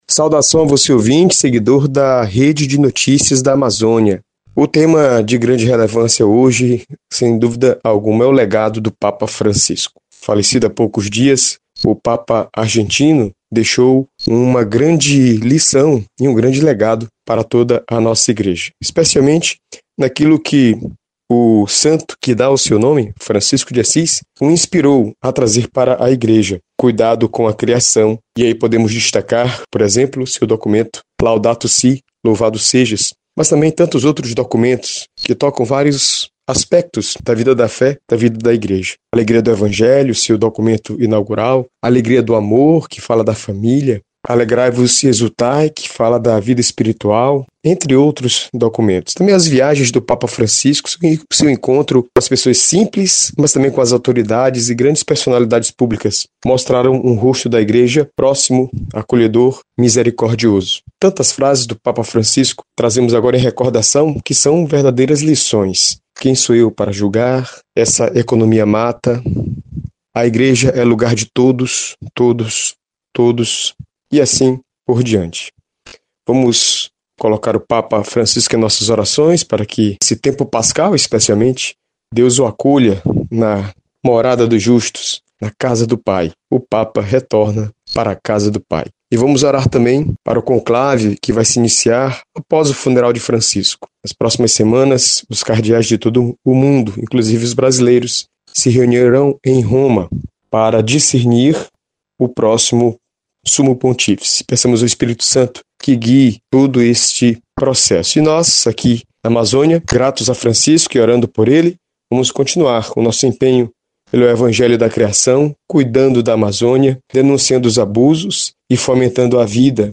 Confira o editorial